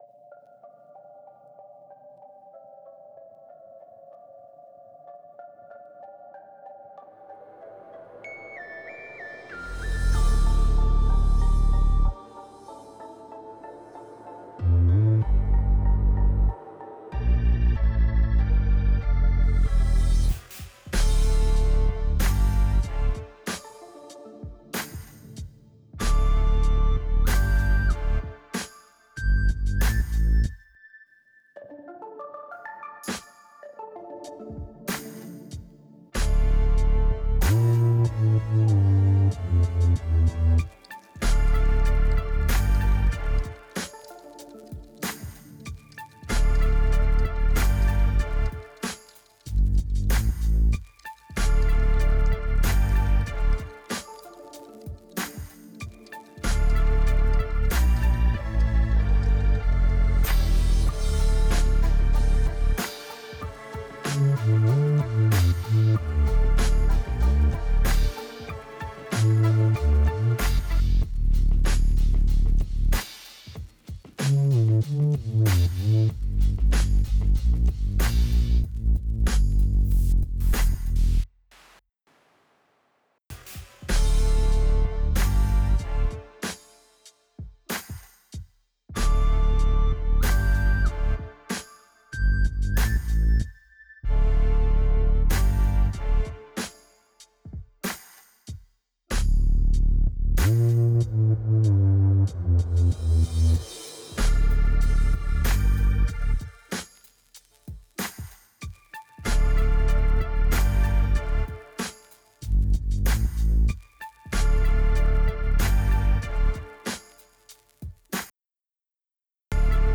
A dark 136 BPM piece in C minor.